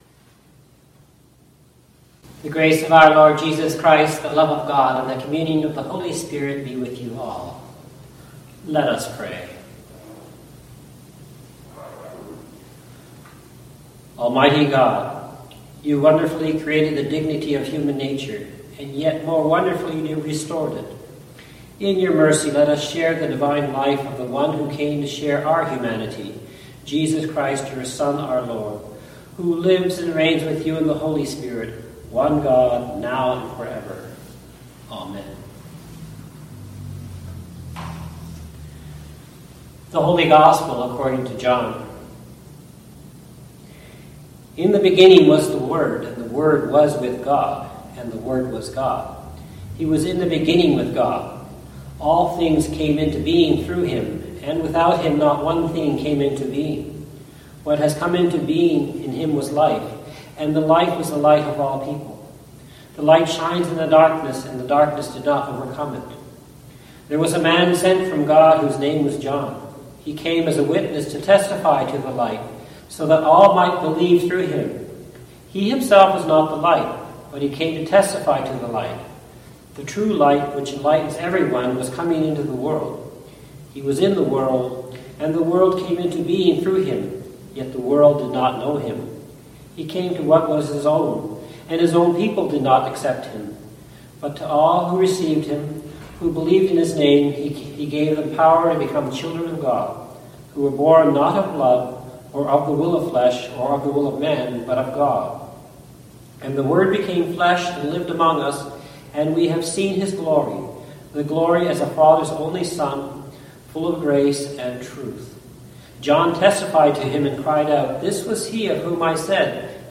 SERMON ONLY (audio)